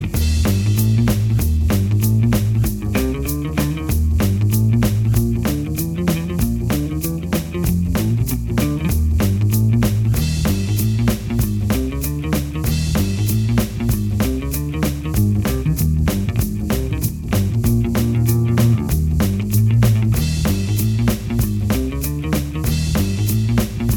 Minus Guitars Pop (1960s) 1:55 Buy £1.50